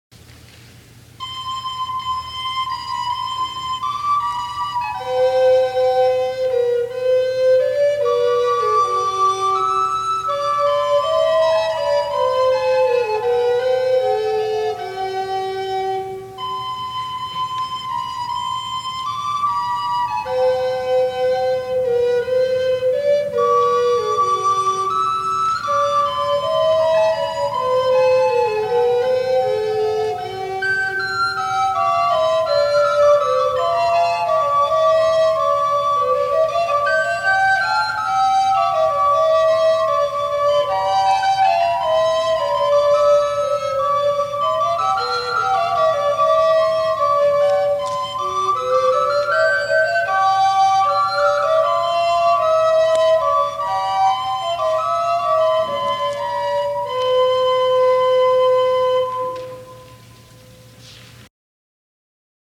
This little, almost canonic, duo is just one of a large number of different settings of what must have been a well-known folk-song.
recorders